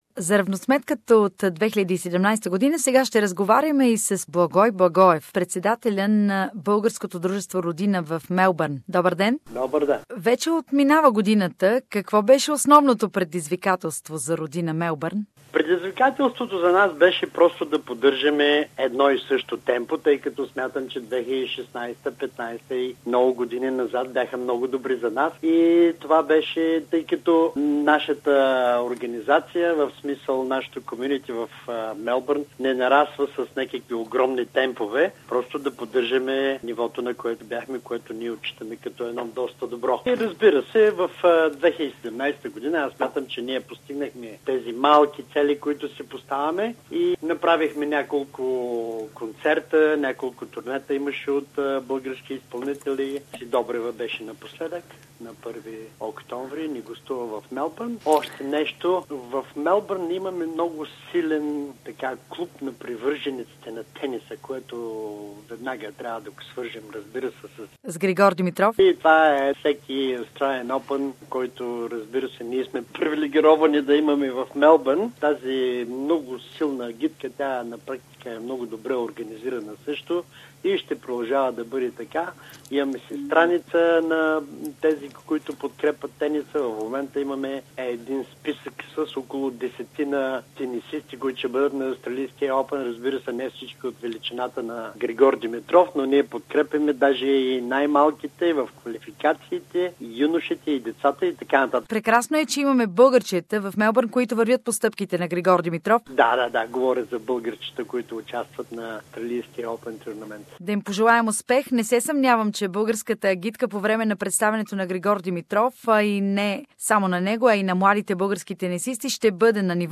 За равносметката от отминаващата си 2017-та година и с поглед към бъдещето. Интервю